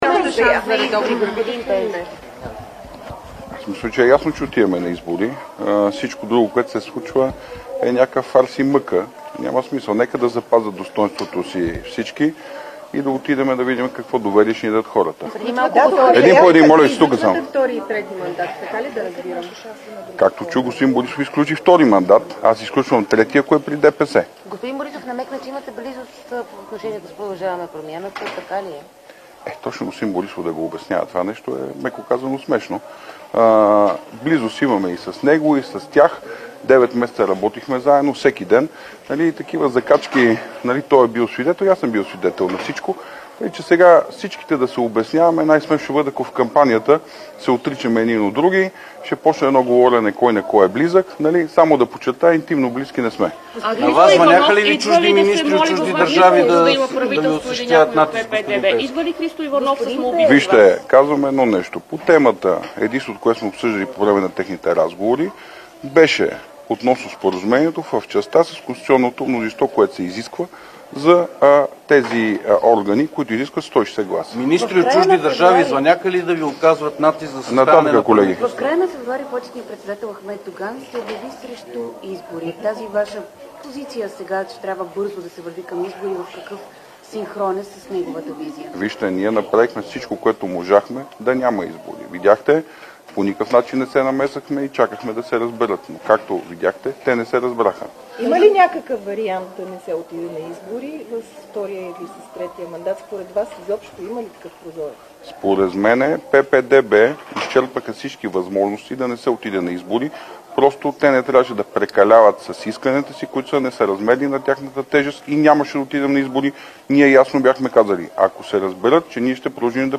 Директно от мястото на събитието